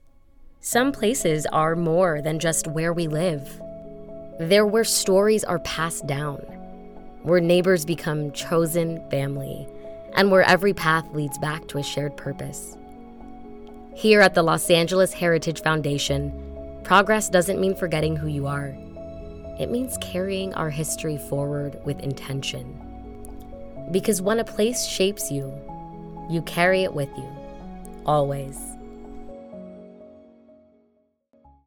Narration - Community Campaign, Community, Values, Warm
English - USA and Canada